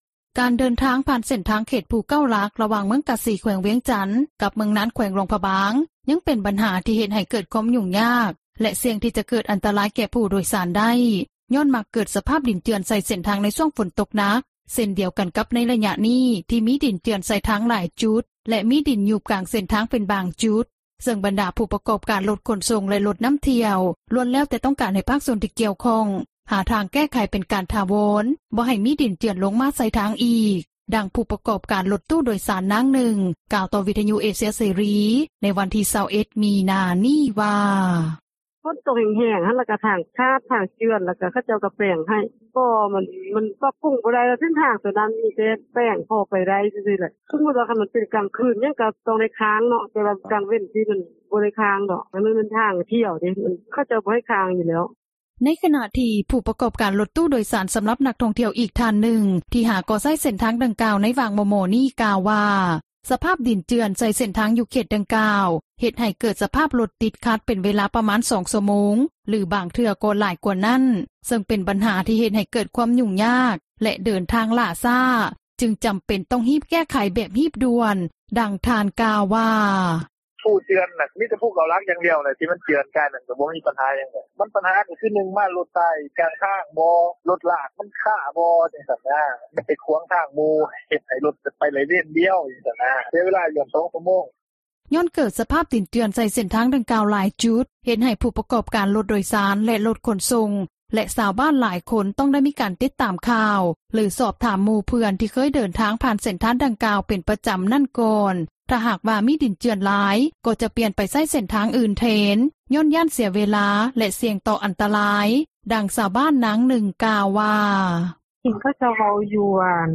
ດັ່ງຜູ້ປະກອບການຣົຖຕູ້ໂດຍສານ ນາງນຶ່ງ ກ່າວຕໍ່ວິຍຸເອເຊັຽເສຣີ ໃນວັນທີ່ 21 ມິນາ ນີ້ວ່າ:
ດັ່ງຊາວບ້ານນາງນຶ່ງ ກ່າວວ່າ: